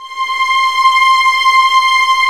Index of /90_sSampleCDs/Roland LCDP13 String Sections/STR_Violins I/STR_Vls2 Arco